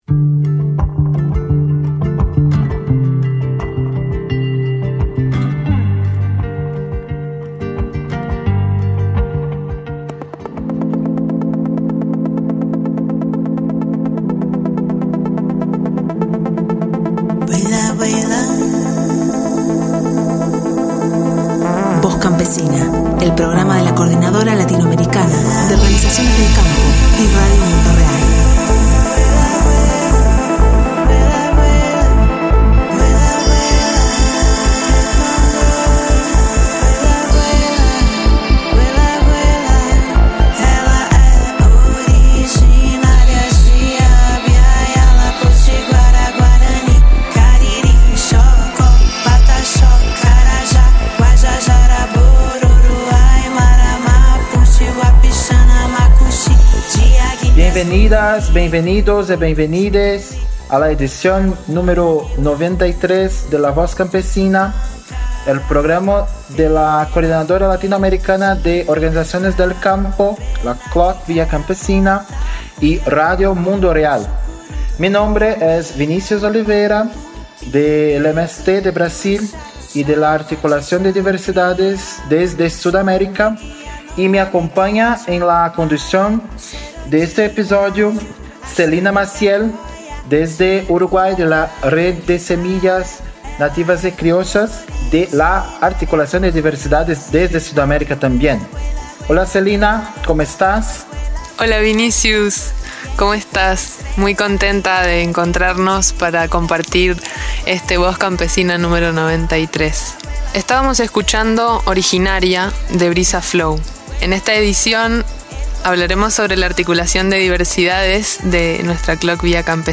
Con audios desde Brasil, Puerto Rico, Paraguay, El Salvador, de la Articulación de Diversidades de Suramérica e incluso a nivel de Vía Campesina Internacional, y la música que siempre enriquece artística y políticamente nuestros programas, les ofrecemos este Voz Campesina.